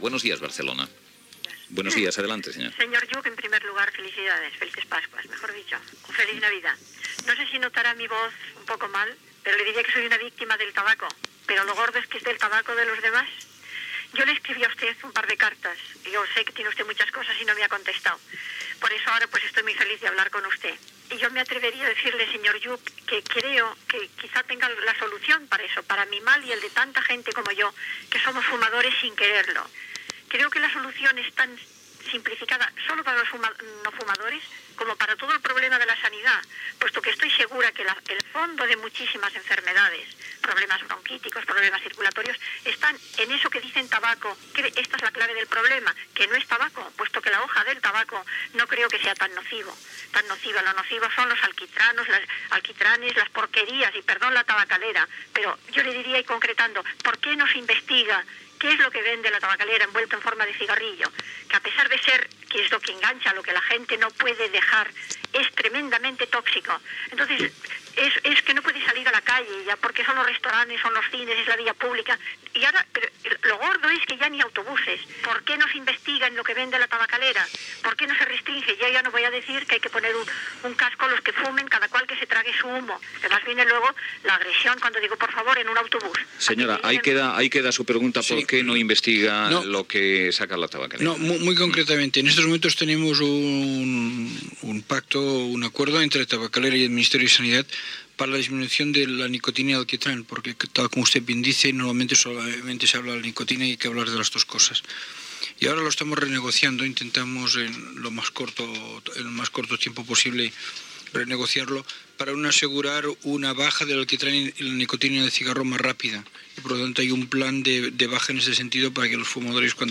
Una oient parla del tabac amb el Ministre de Sanitat Ernest Lluch
Info-entreteniment